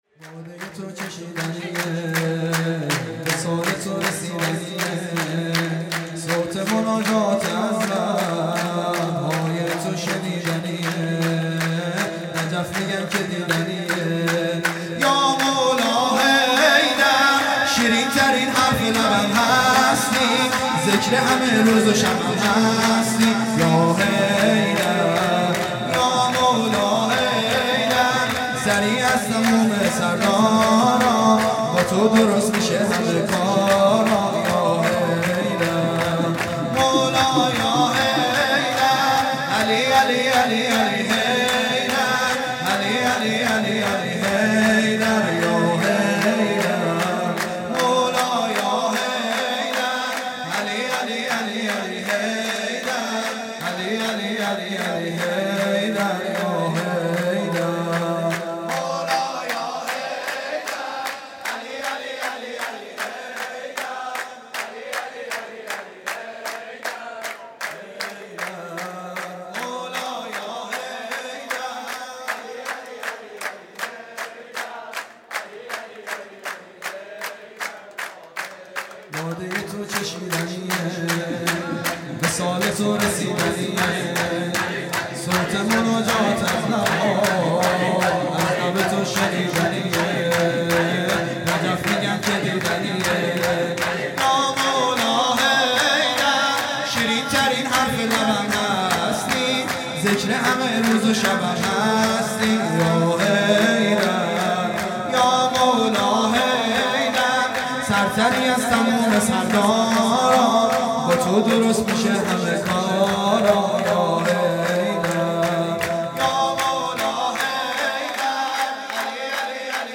هیئت دانشجویی فاطمیون دانشگاه یزد
سرود
ولادت پیامبر (ص) و امام صادق (ع) | ۳ آذر ۱۳۹۷